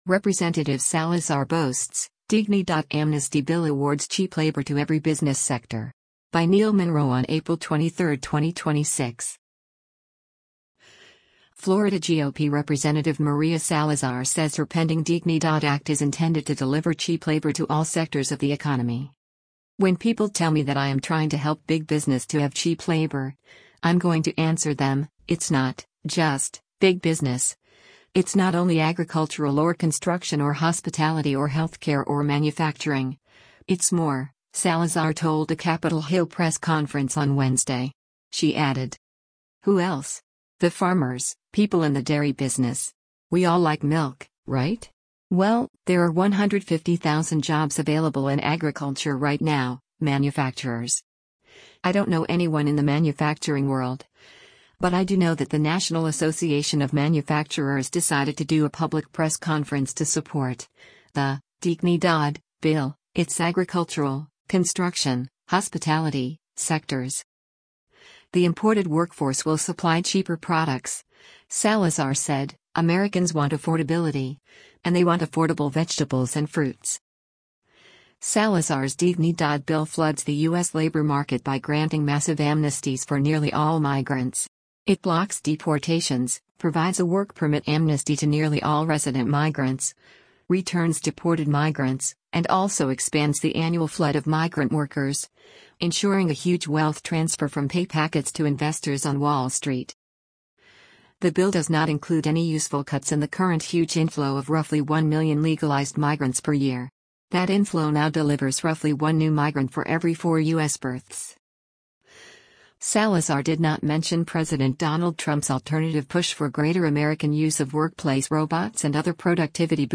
“When people tell me that I am trying to help big business to have cheap labor, I’m going to answer them: It’s not [just] big business, it’s not only agricultural or construction or hospitality or health care or manufacturing — it’s more,” Salazar told a Capitol Hill press conference on Wednesday.